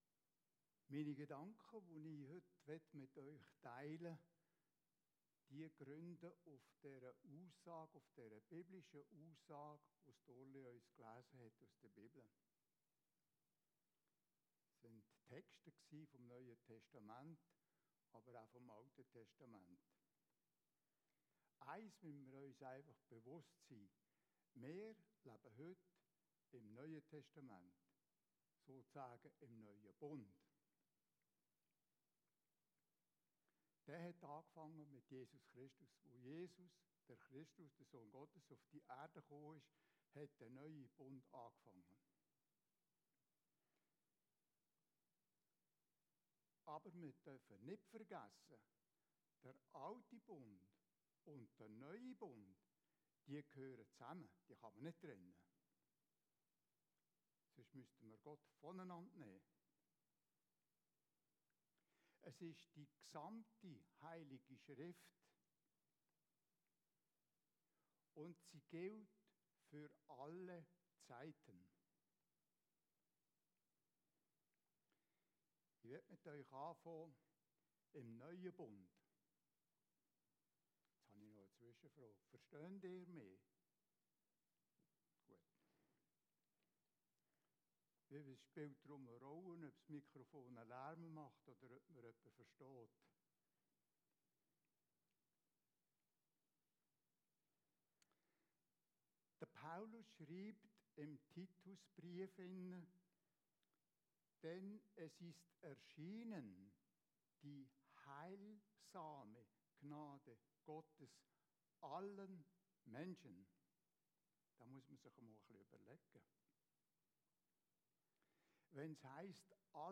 GASTPREDIGT VON 26.07.20